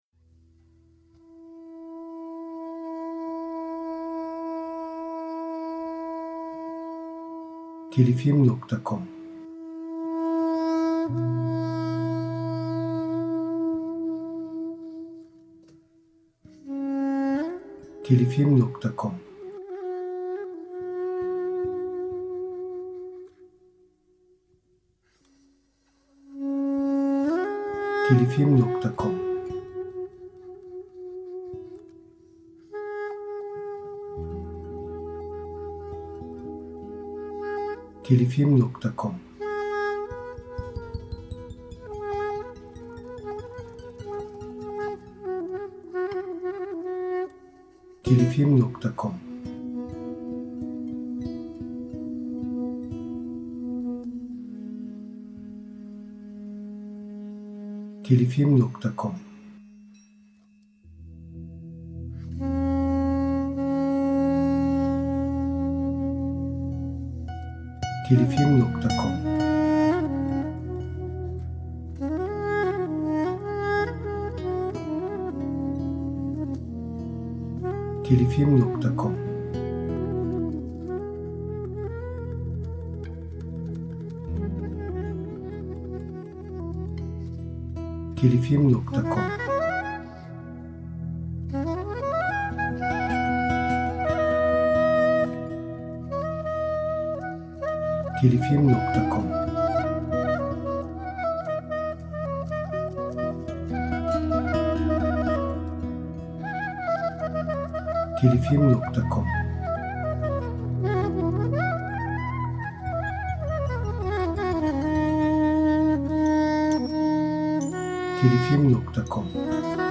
Eser Türü : Müzikal Tema